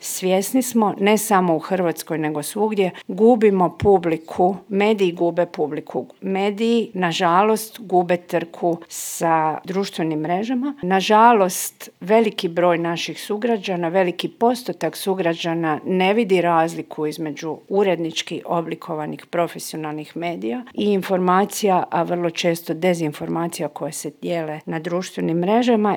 O svemu tome razgovaralo na konferenciji koja je okupila novinare, urednike, medijske stručnjake, ali i studente.
Ministrica kulture i medija Nina Obuljen Koržinek ističe da je ova tema izrazito važna: